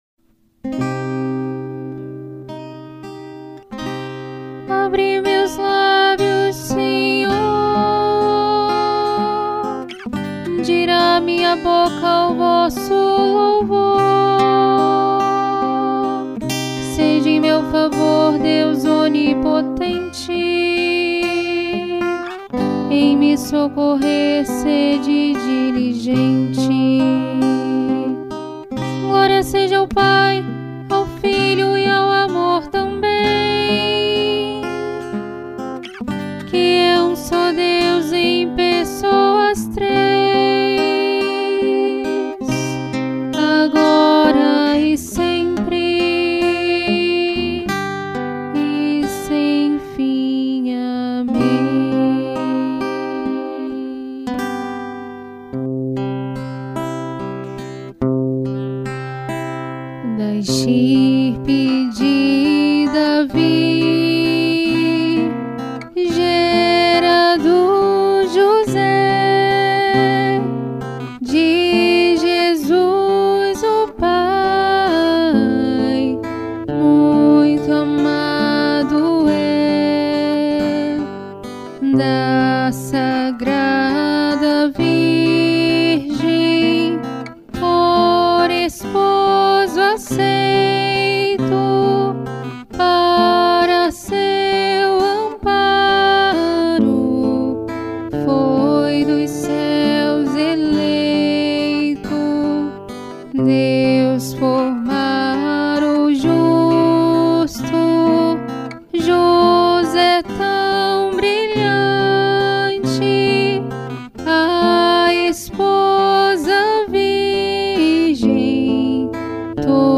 Ofício ao Glorioso São José cantado